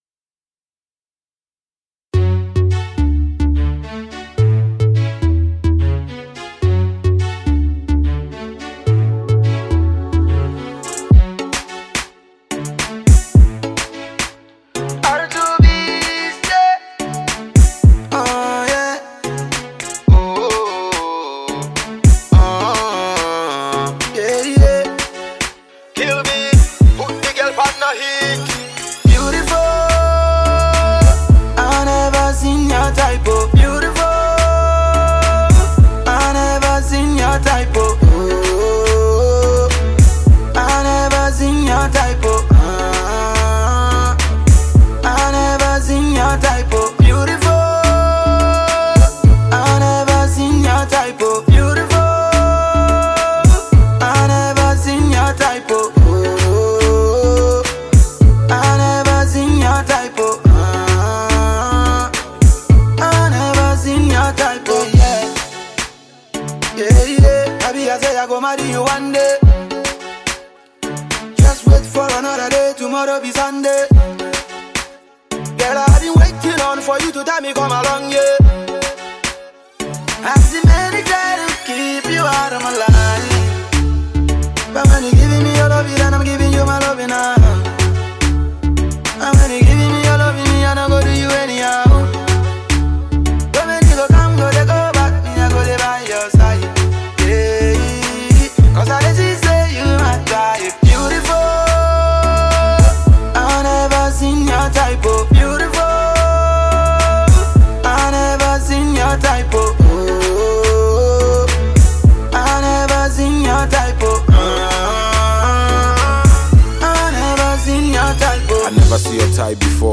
the duo have released a brand new song .
groovy bop